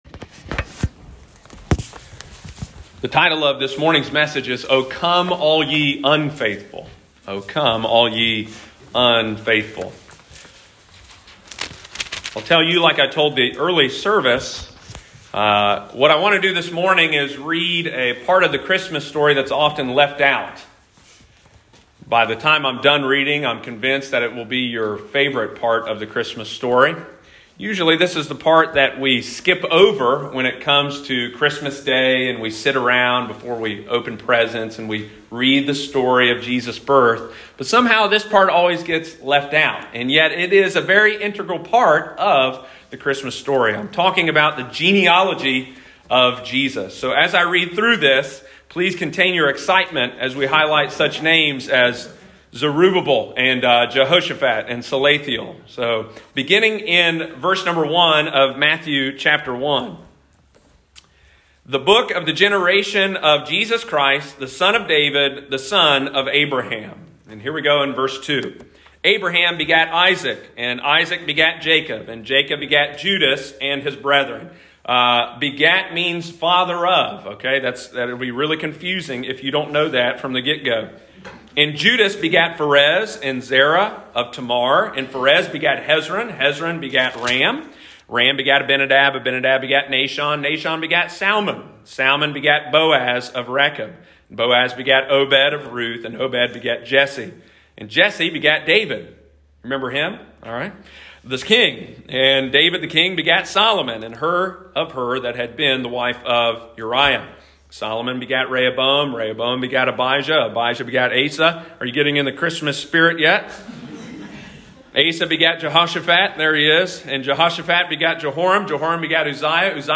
He came not for the faithful but the unfaithful. Sunday morning, December 19, 2021.